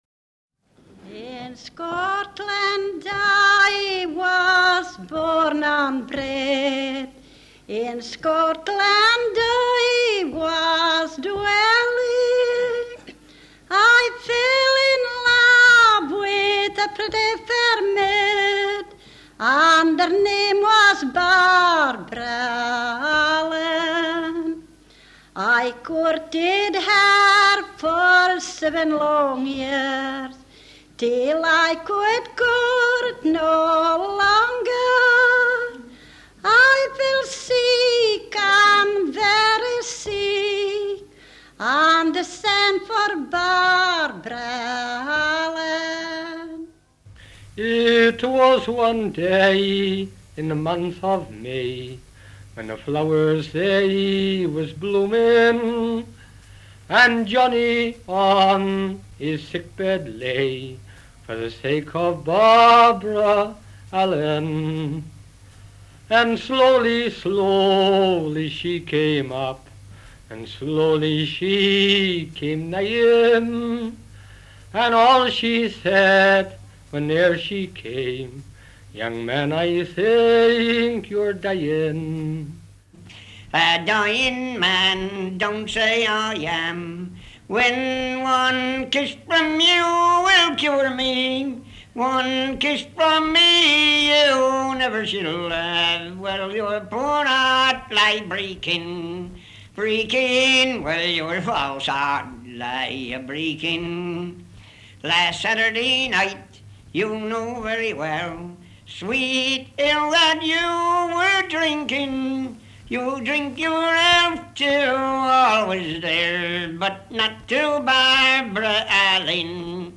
BALLATE DA INGHILTERRA, SCOZIA E IRLANDA